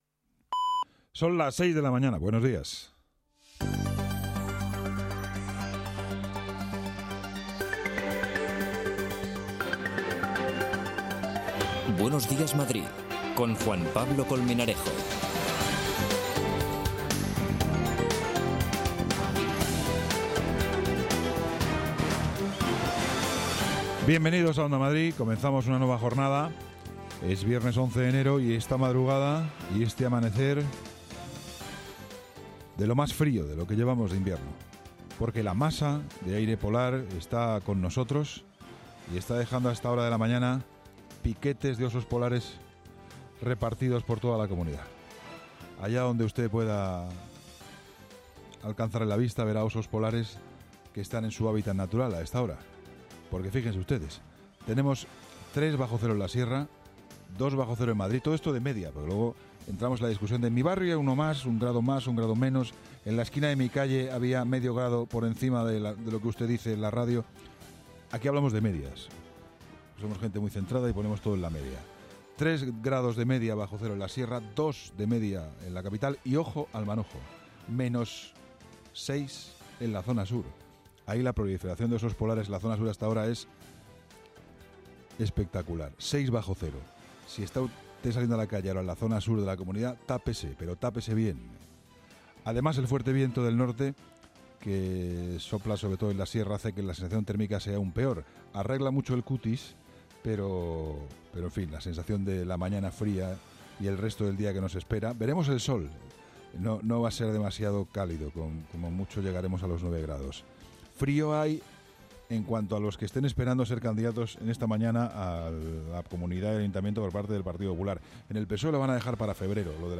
La Ronda de corresponsales con las previsiones del día en los municipios de la región.